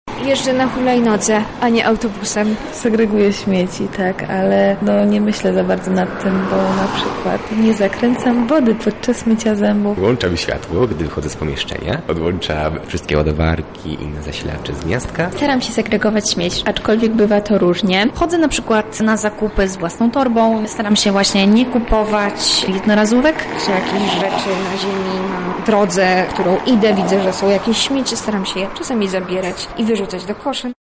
Zapytaliśmy lublinian, jak oni na co dzień pomagają Ziemi:
sonda